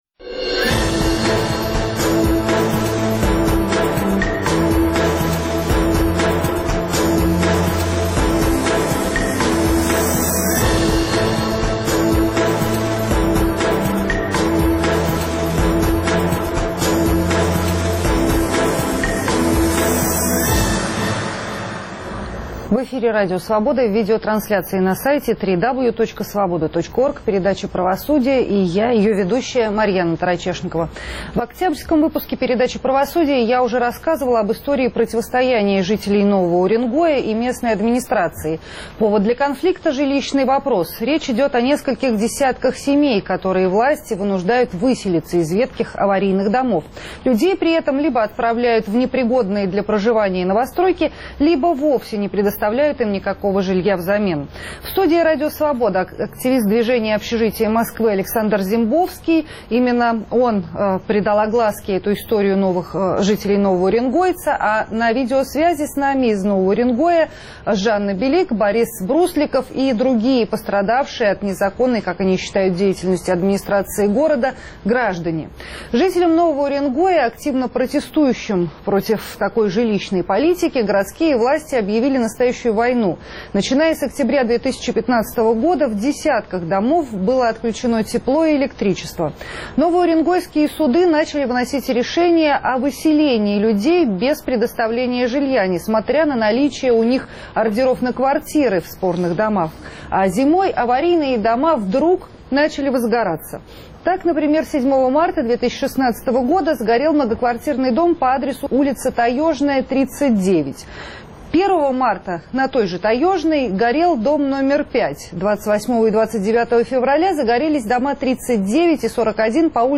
В Новом Уренгое людей лишают единственного жилья. В студии Радио Свобода